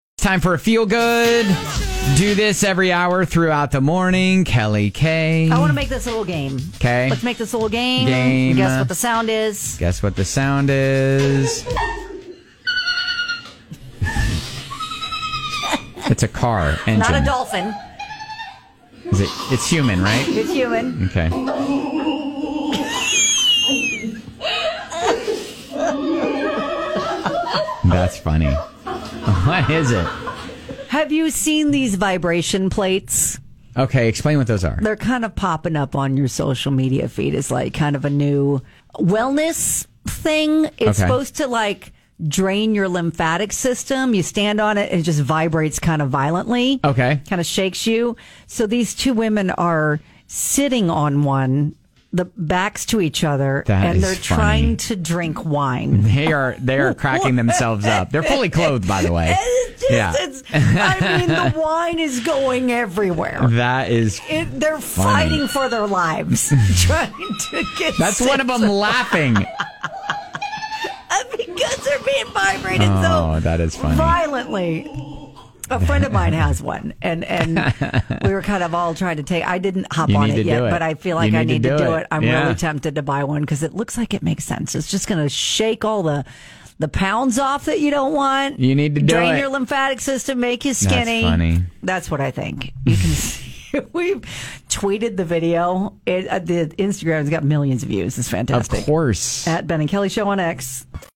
Two women hilariously try to sip wine while sitting back to back on a vibration plate!